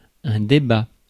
Ääntäminen
Synonyymit talks discourse agitate discuss contest contend Ääntäminen US UK : IPA : /dɪˈbeɪt/ Lyhenteet ja supistumat (laki) Deb.